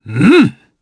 Roman-Vox_Happy4_jp.wav